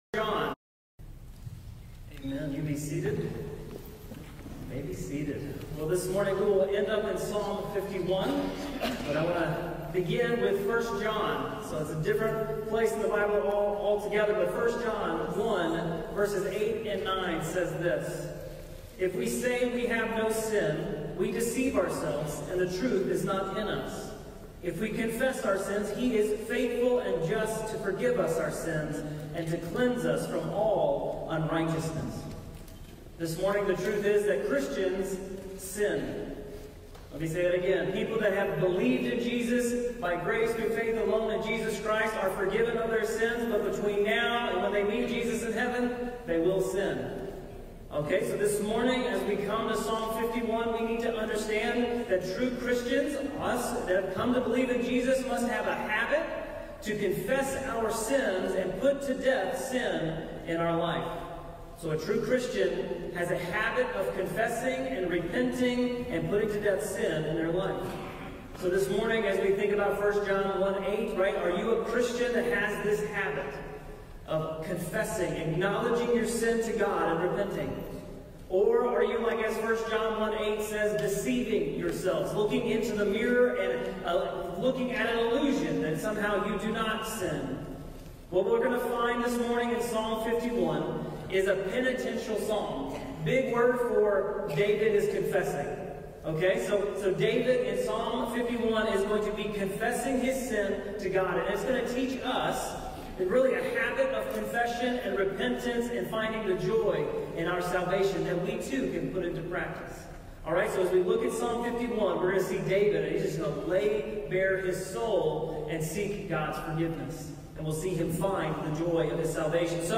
Sermons | Longbranch Community Baptist Church